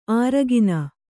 ♪ āragina